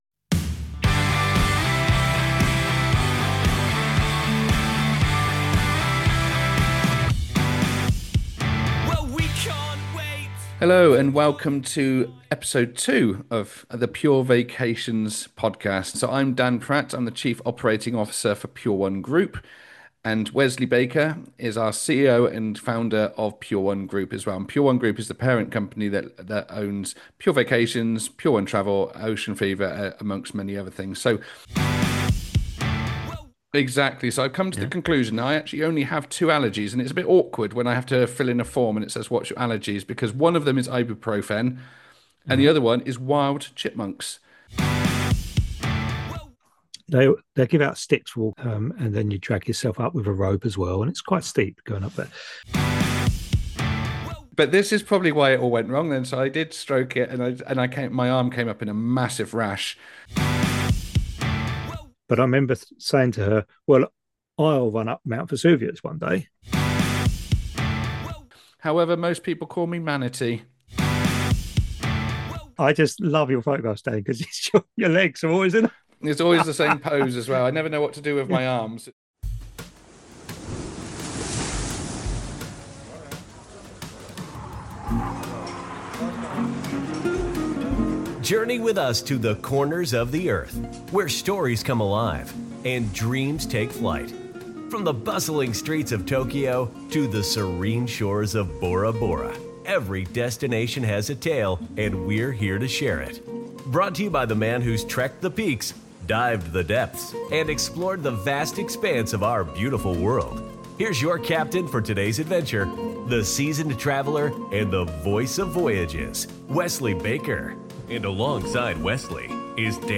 This is a light, engaging conversation designed to spark curiosity and encourage listeners to think about their own adventures.